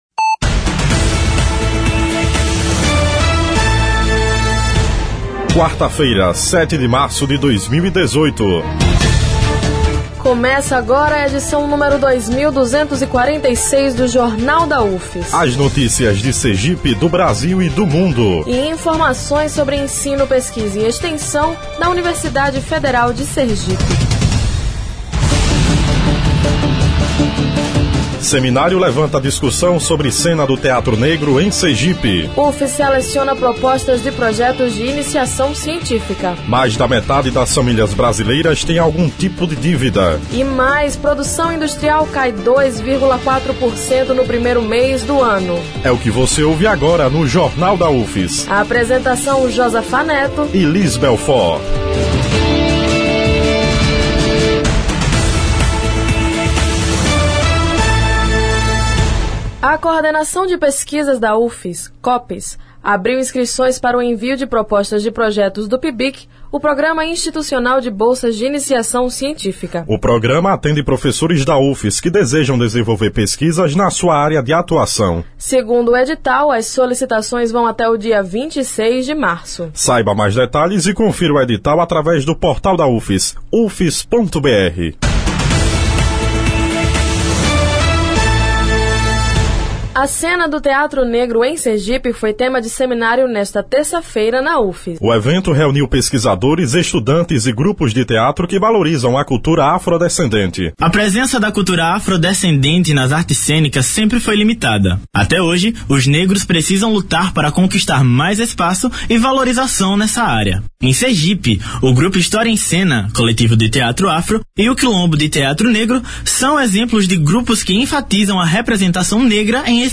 O Jornal da UFS desta quarta-feira, 07, repercute o Seminário Teatro Negro: A cena em Sergipe. O evento reuniu pesqusiadores, estudantes e grupos de teatro que exploram a cultura afrodescendente. O noticiário vai ao ar às 11h, com reprises às 17h e 22h.